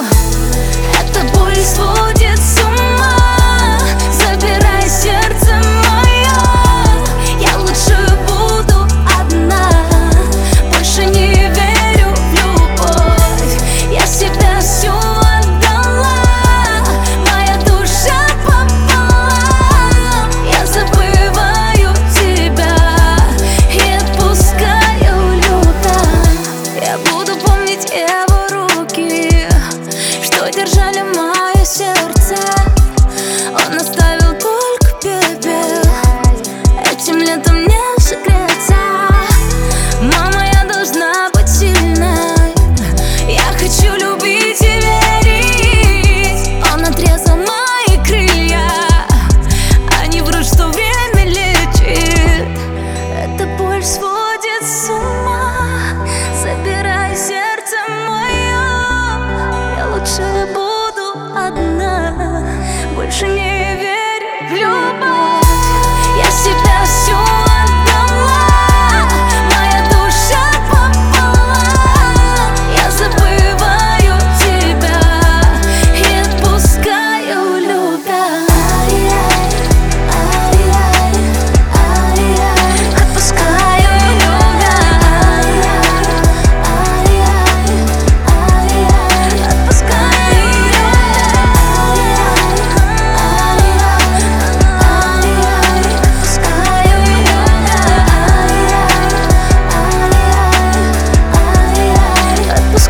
• Качество: 320, Stereo
грустные
красивый женский вокал